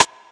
Teck-Snare (more chunez)).wav